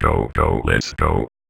VVE1 Vocoder Phrases
VVE1 Vocoder Phrases 22.wav